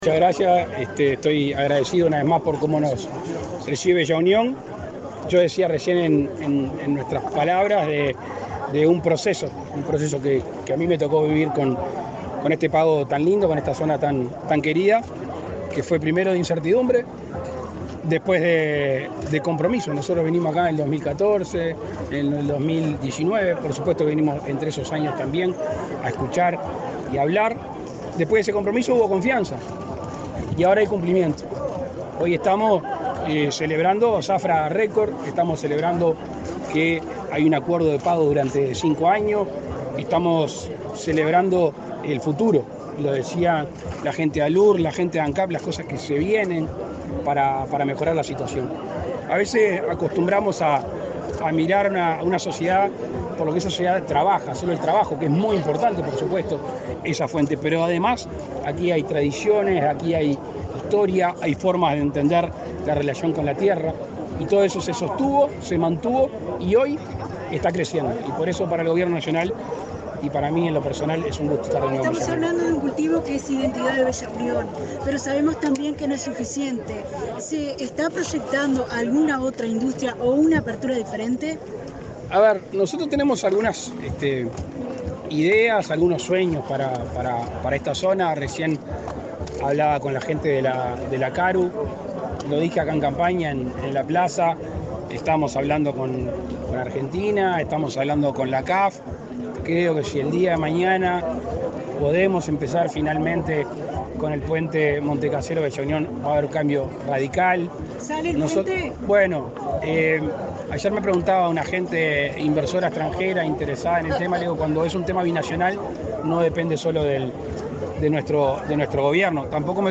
Declaraciones del presidente Lacalle Pou a la prensa
El presidente de la República, Luis Lacalle Pou, presidió en Artigas el acto por el fin de la zafra de la caña de azúcar. Luego dialogó con la prensa.